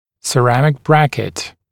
[sə’ræmɪk ‘brækɪt][сэ’рэмик ‘брэкит]керамический брекет